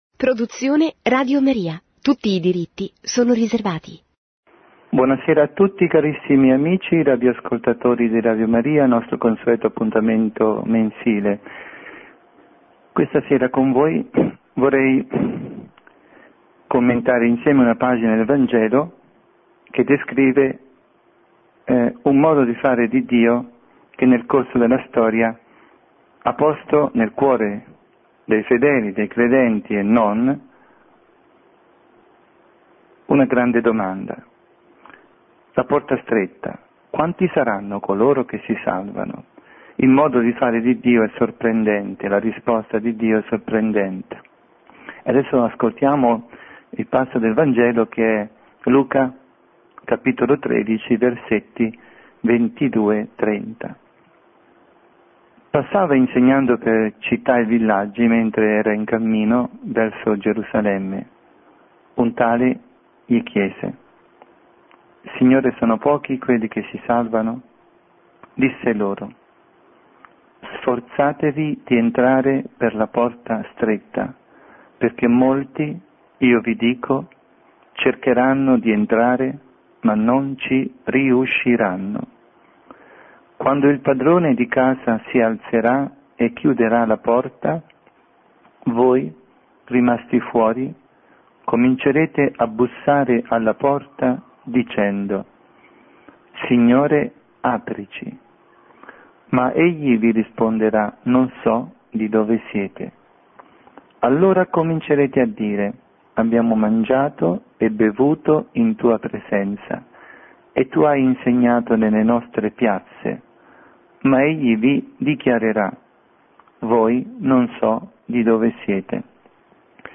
Catechesi
trasmessa in diretta su RadioMaria